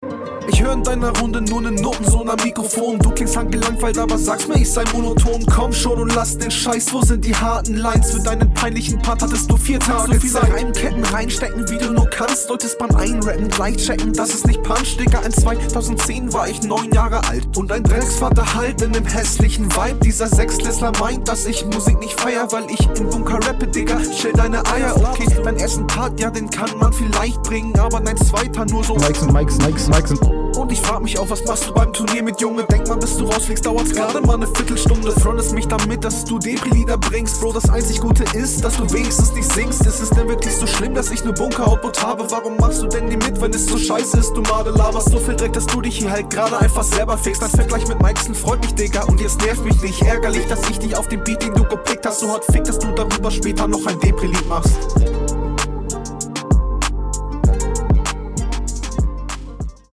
Qualität besser als HR, schade das nicht so klingt in der HR. Aber starke RR, …
Flow: Stimmeinsatz fand ich wieder sehr gut, fand den Flow sogar noch ein Stückchen besser …